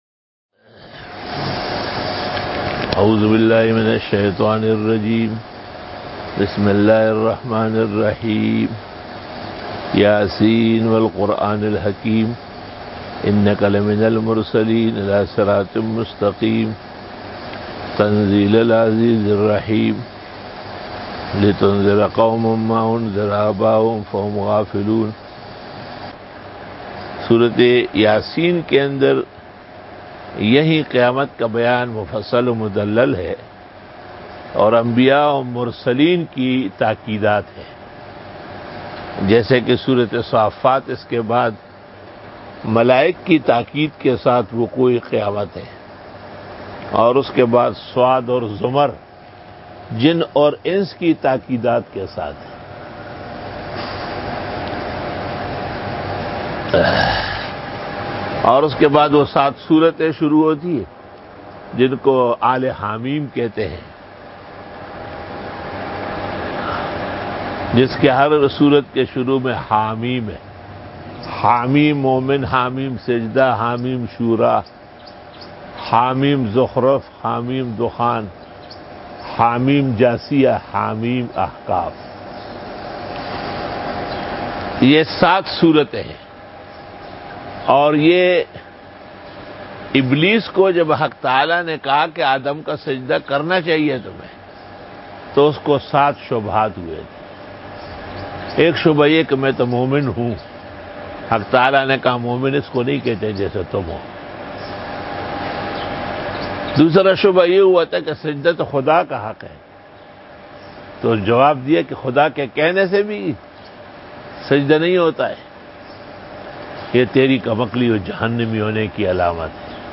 54 Quran Tafseer 06 Jun 2020 (14 Shawwal 1441 H) Saturday Day 54